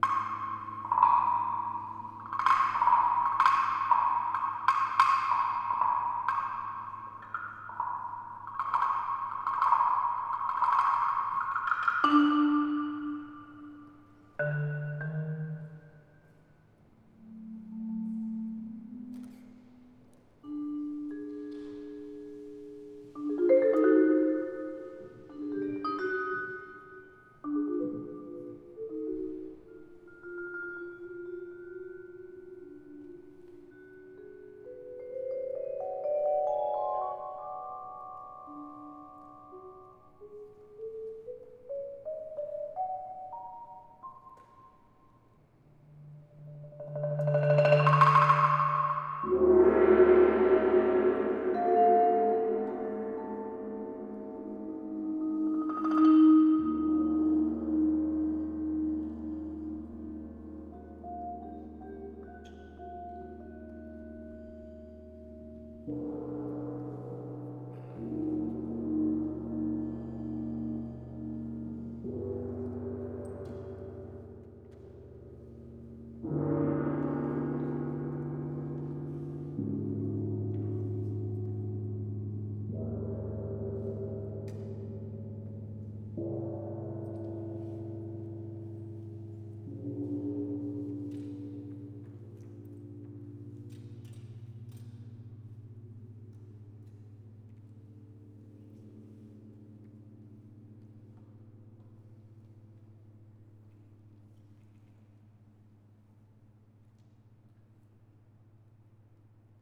KONZERT FÜR 50 WINDGONGS
und kleines Ensemble
(Kopfhörer empfohlen. Ein Großteil der Frequenzen wird von Laptop-Lautsprechern nicht abgebildet)
Jeder Windgong hat eine andere Ansprache und eine individuelle Obertonstruktur.
Wenn die Trompete verstummt ist, hallt ihre Melodie als Cluster in den leise vibrierenden Tamtams weiter.